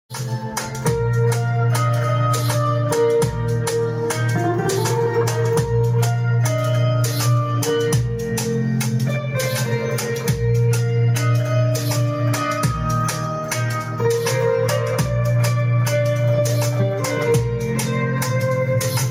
sección de guitarra 🎸 que sound effects free download